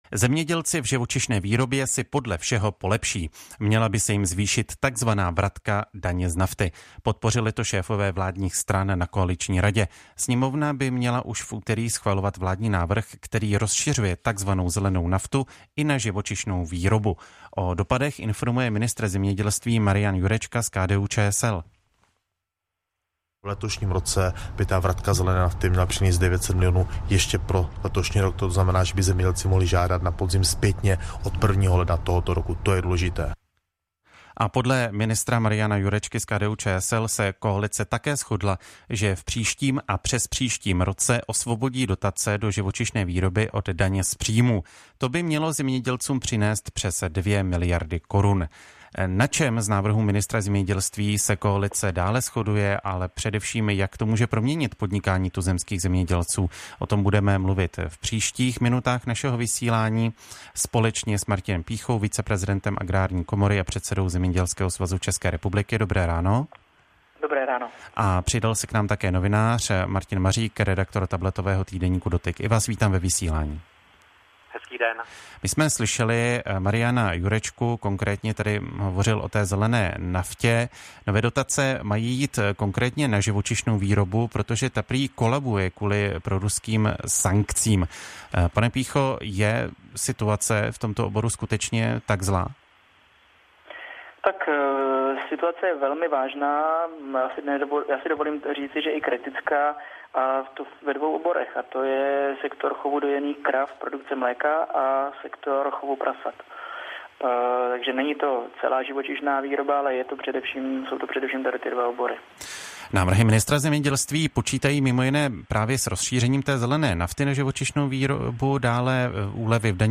ČR Plus: Rozhovor s předsedou svazu k dotacím tzv. zelené nafty